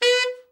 TENOR SN  28.wav